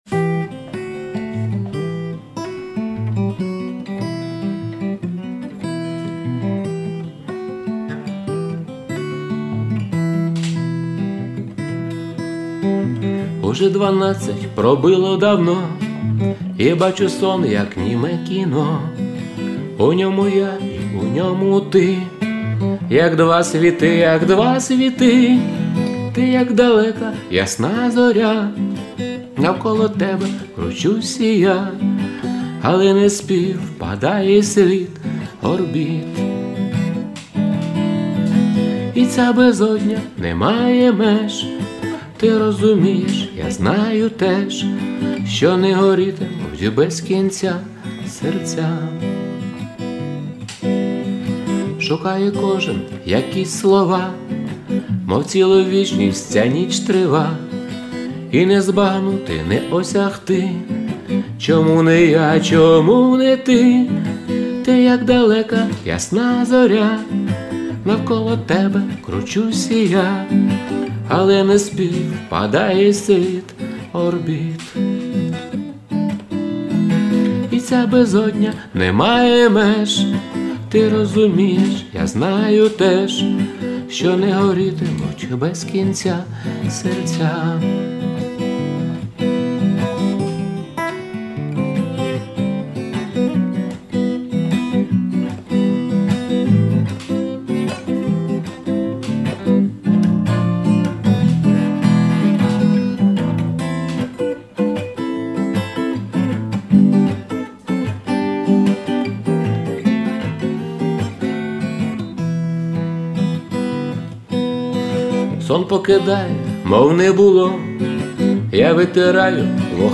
класний голос... 16
Люблю пісні під гітару, гарно, дуже сподобалось і голос такий приємний. give_rose